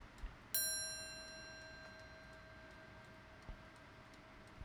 Механические часы
Мелодии
Тип часов с маятником